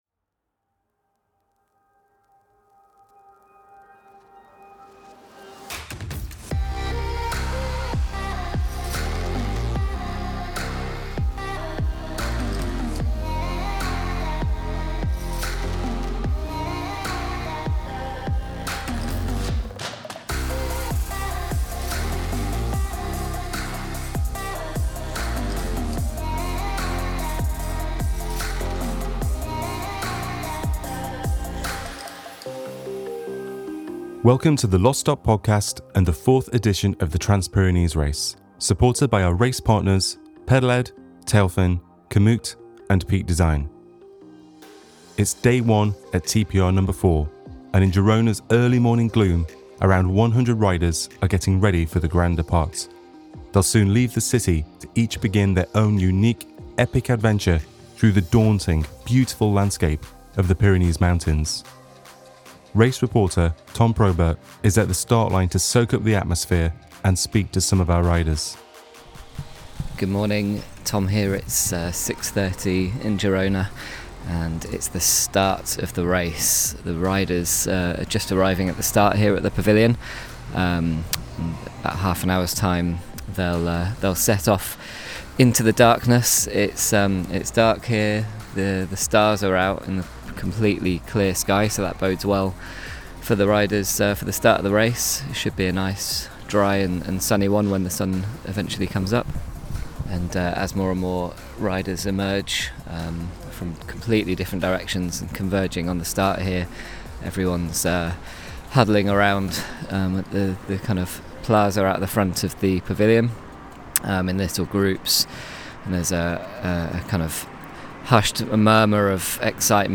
Rider interviews and recordings from our observant Race Reporters, you couldn’t get closer without being in the Pyrenees yourself.